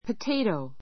pətéitou ポ テ イトウ